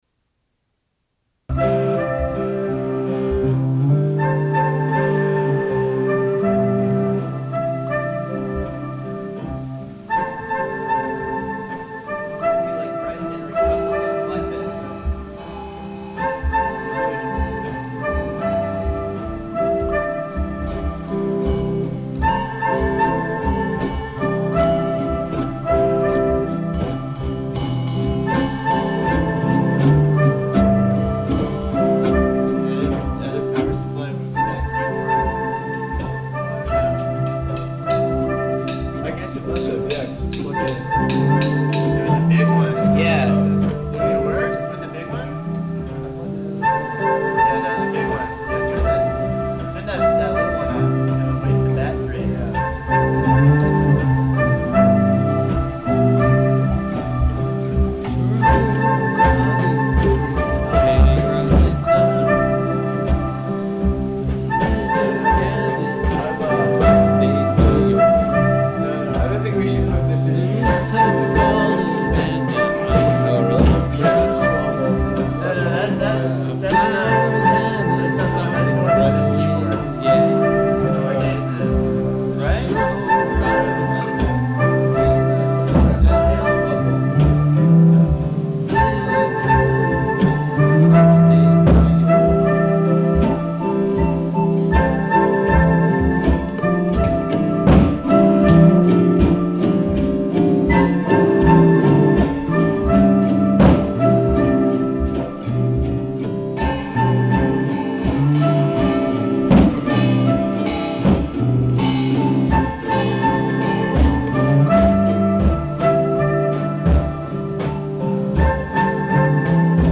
06 piano wave.mp3